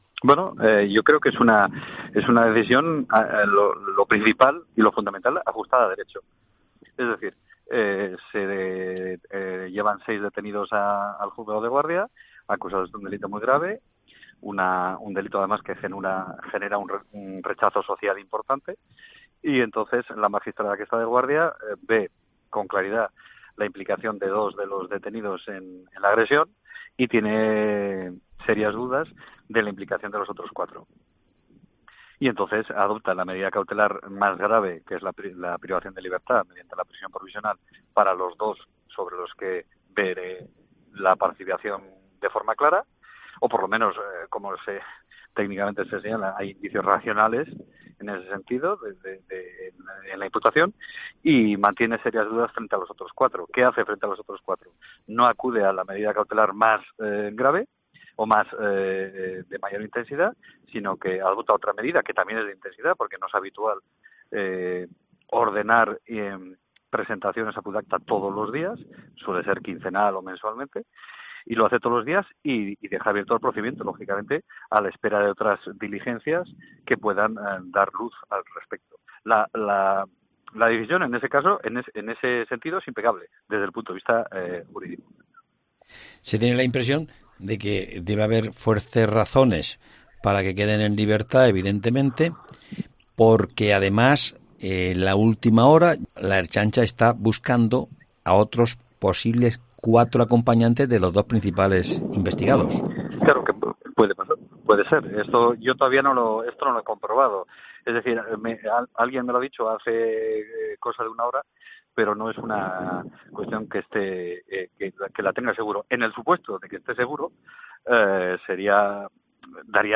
"Y cuando alguien públicamente hace exigencias y carga contra los jueces, que vea que una cosa es la denuncia y otra es la labor de los jueces", ha dicho en una entrevista a COPE.